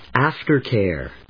音節áfter・càre